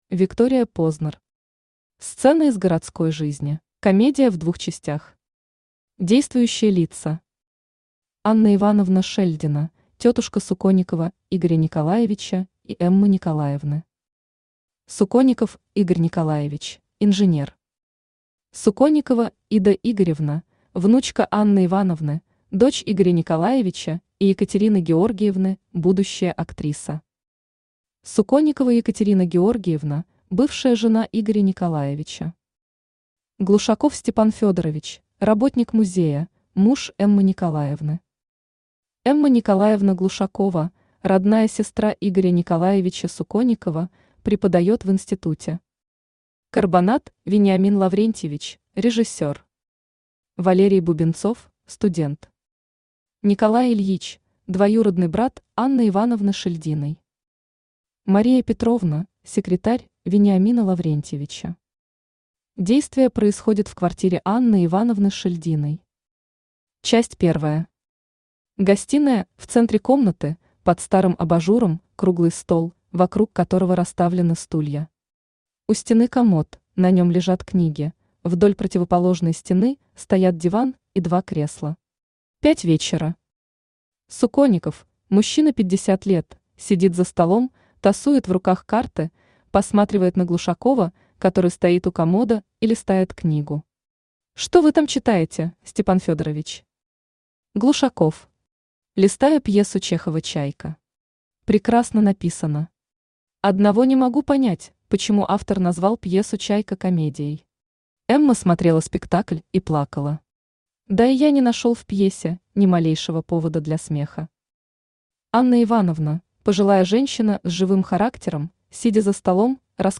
Aудиокнига Сцена из городской жизни Автор Виктория Познер Читает аудиокнигу Авточтец ЛитРес.